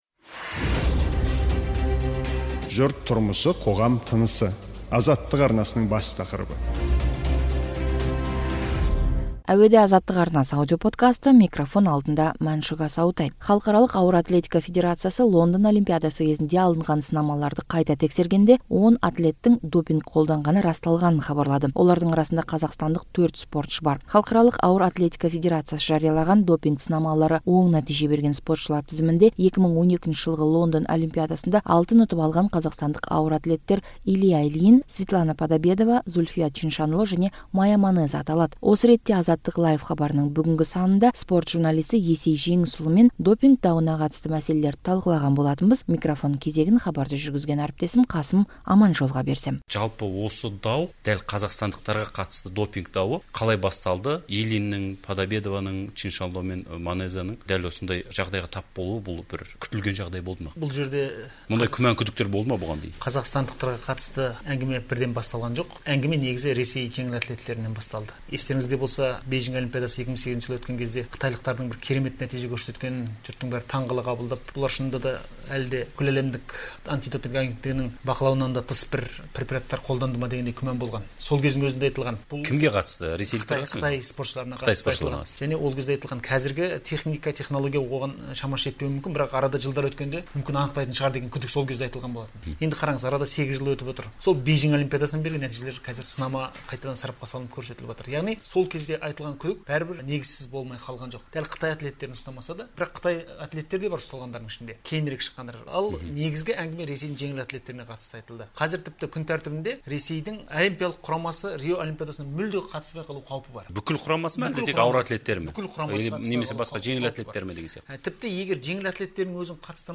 Допинг дауы туралы сарапшы сөйлейді